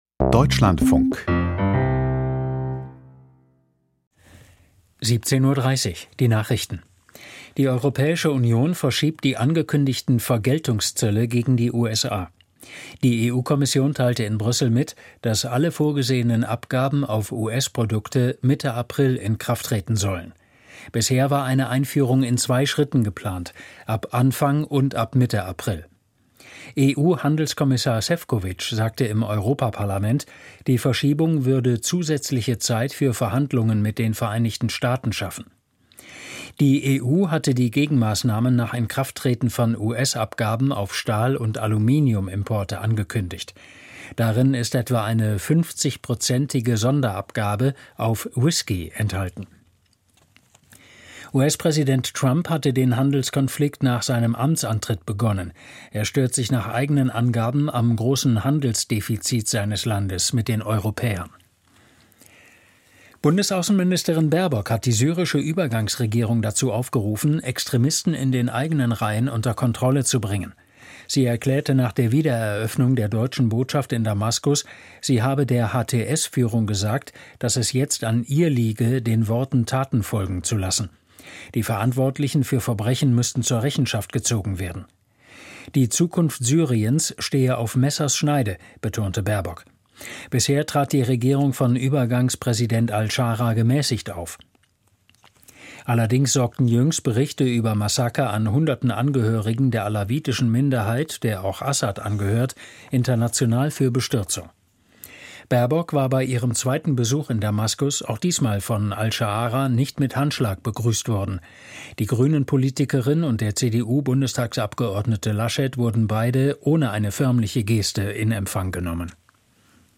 Die Deutschlandfunk-Nachrichten vom 20.03.2025, 17:30 Uhr